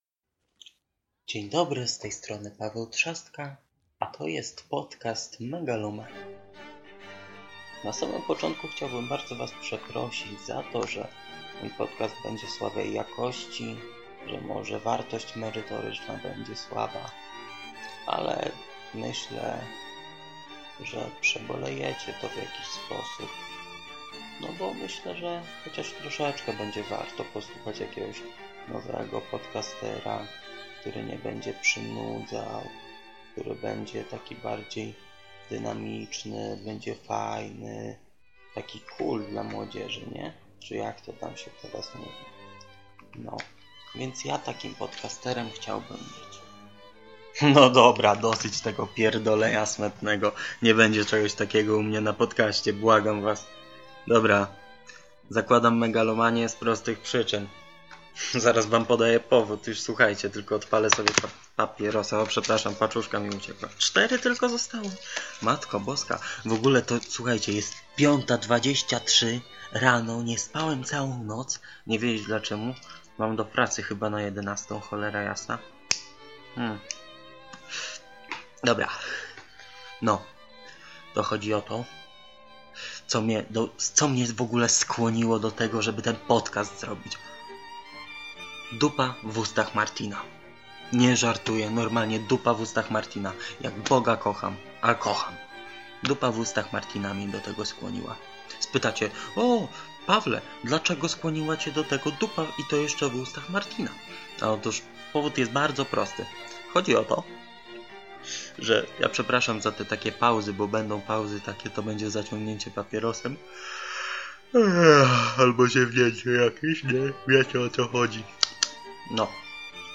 Megalomania jest audycją o wszystkim